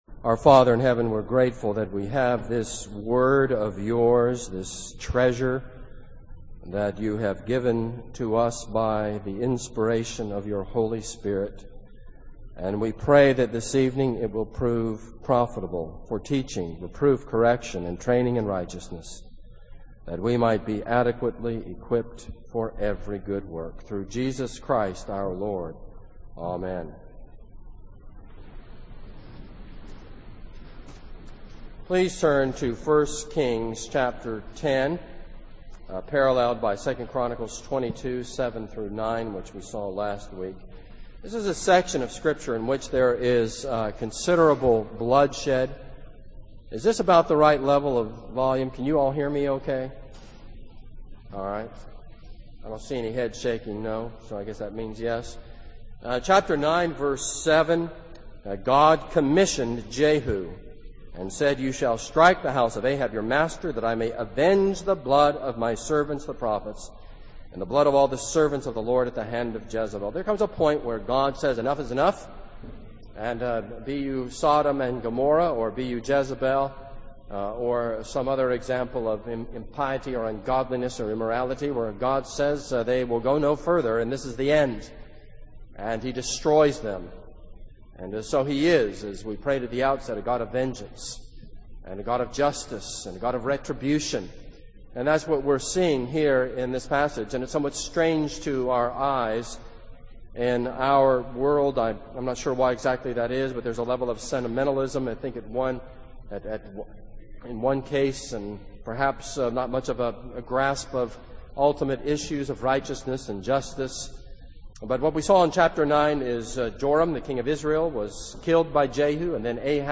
This is a sermon on 2 Kings 10.